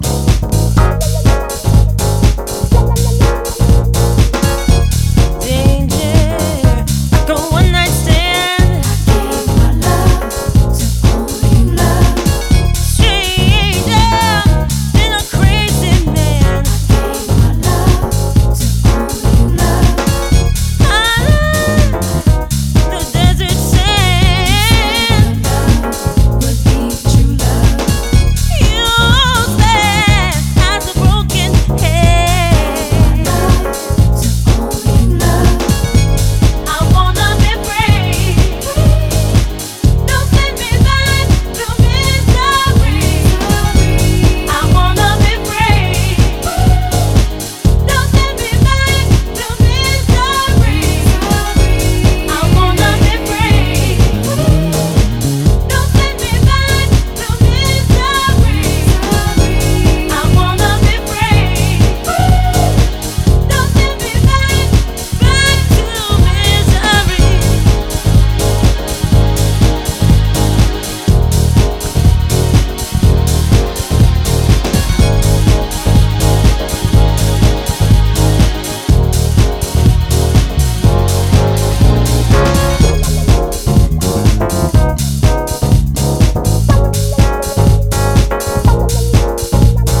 ジャンル(スタイル) DEEP HOUSE / SOULFUL HOUSE